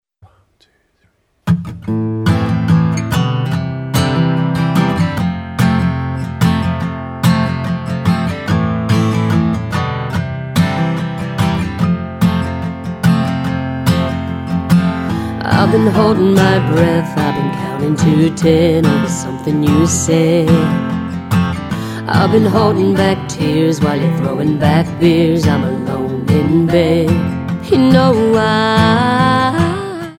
--> MP3 Demo abspielen...
Tonart:Am Multifile (kein Sofortdownload.
Die besten Playbacks Instrumentals und Karaoke Versionen .